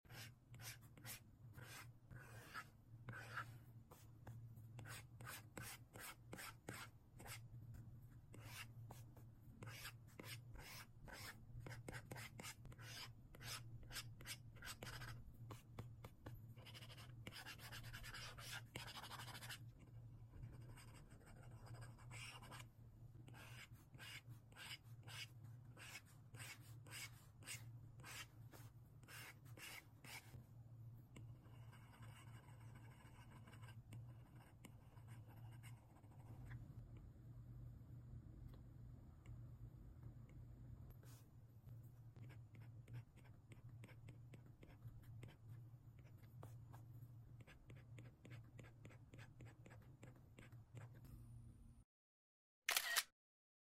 Drawing white noise, enjoy the sound effects free download
enjoy the Mp3 Sound Effect Drawing white noise, enjoy the rustling sound.